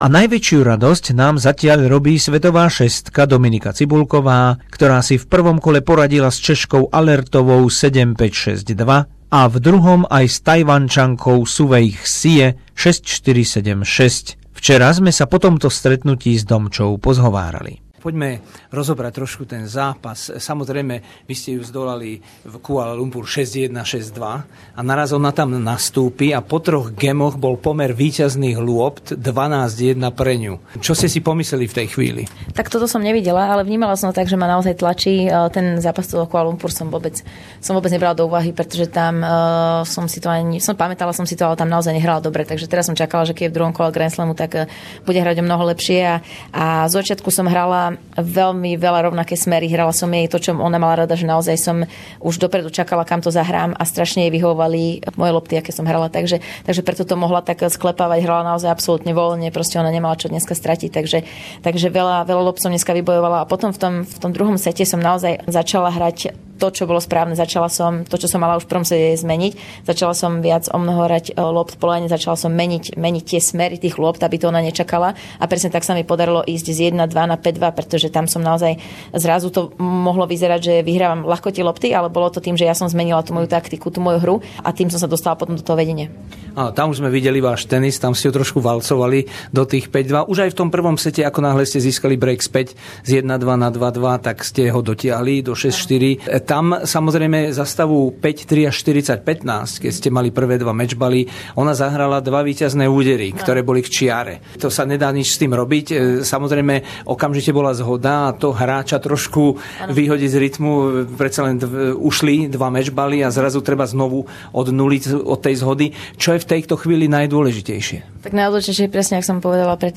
Rozhovor s tenistkou Dominikou Cibulkovou po 4. dni Australian Open v Melbourne a víťazstve nad Tajwančankou Su Wei Hsieh